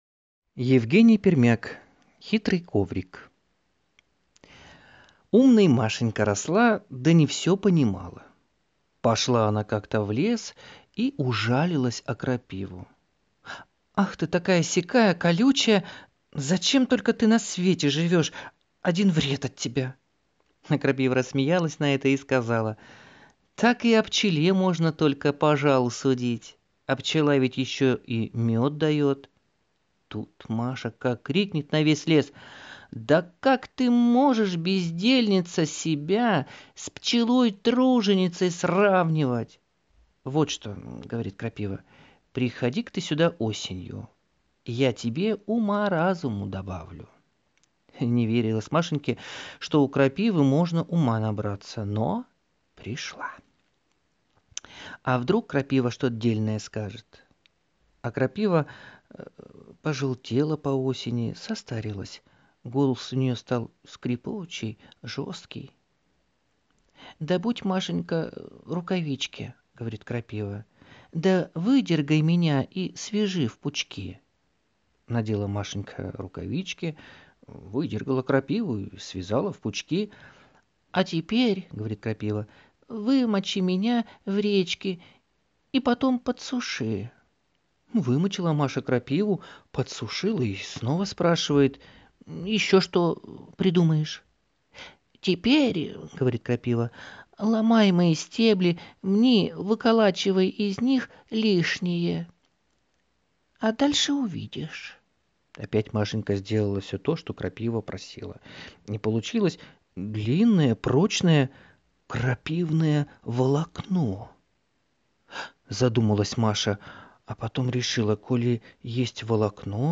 Аудиосказка «Хитрый коврик»